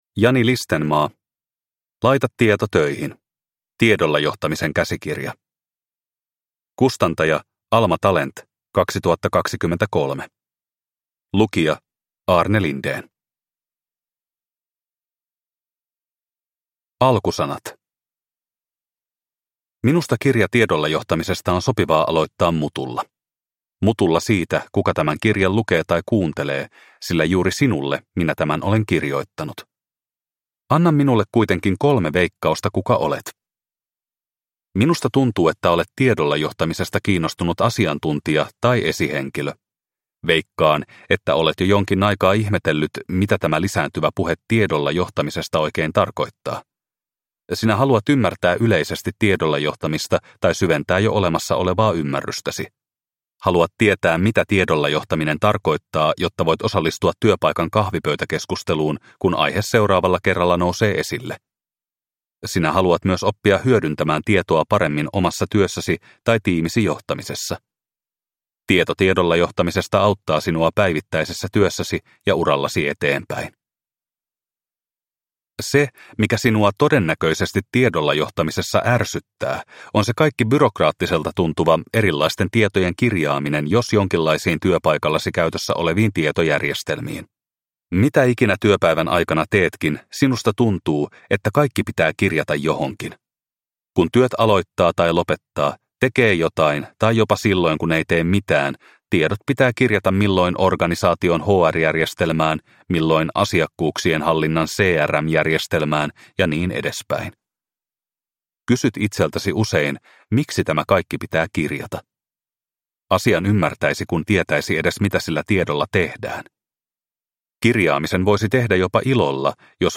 Laita tieto töihin – Ljudbok – Laddas ner